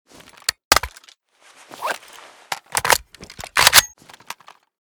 ace21_reload_empty.ogg